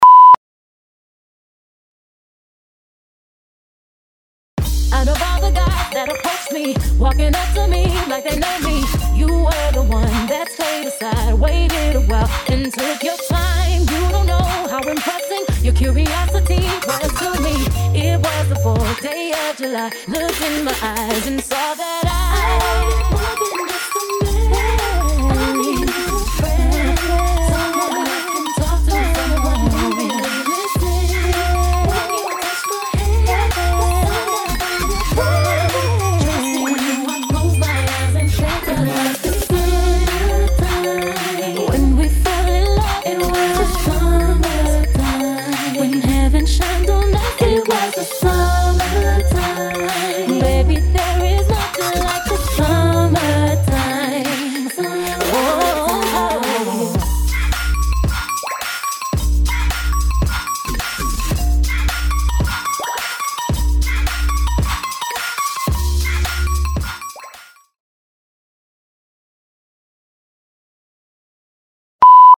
*bleep: Are you on your spot?
*few seconds silence: stay in your place till you hear the next bleep